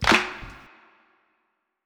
TC2 Clap2.wav